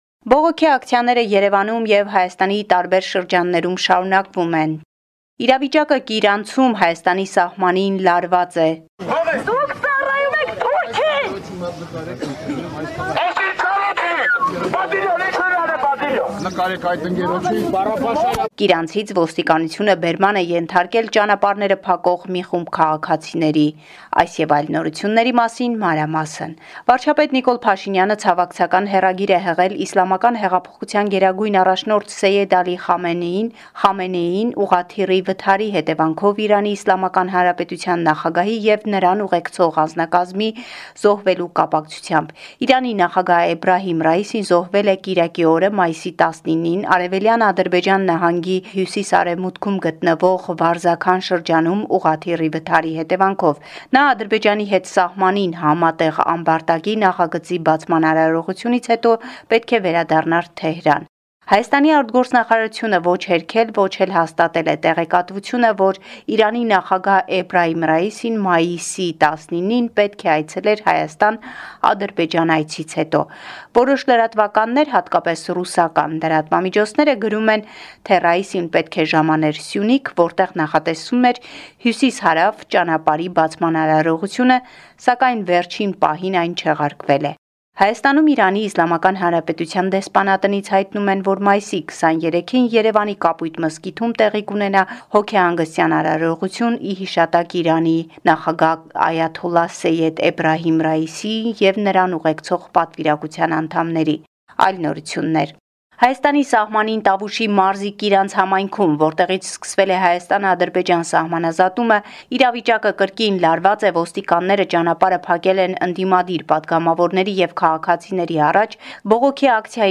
Latest News from Armenia – 21 May 2024